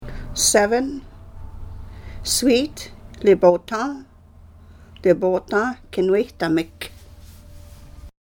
Reading Indigenous Translations of Riel: Heart of the North -- Audio Recordings